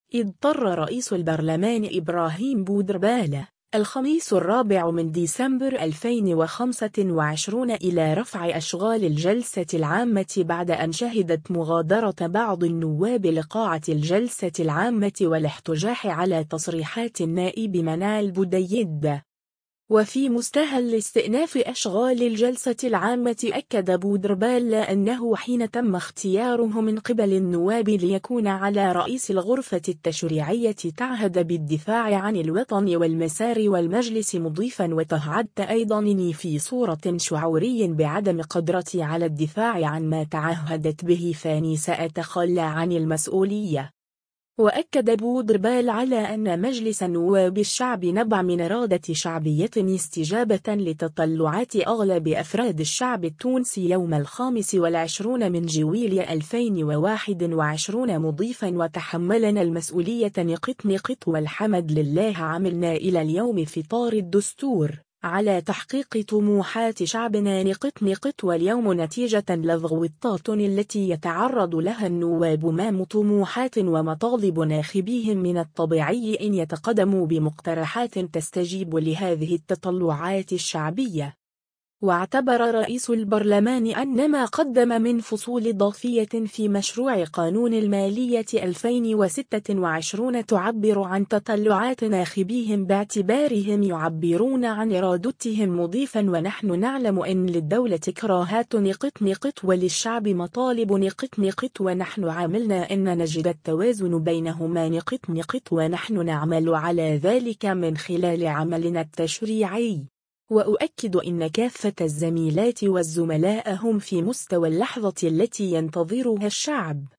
وفي مستهل استئناف أشغال الجلسة العامة أكد بودربالة لى أنه حين تم اختياره من قبل النواب ليكون على رئيس الغرفة التشريعية تعهد بالدفاع عن الوطن والمسار والمجلس مضيفا” وتهعدت ايضا اني في صورة شعوري بعدم قدرتي على الدفاع عن ما تعهدت به فاني سأتخلى عن المسؤولية”.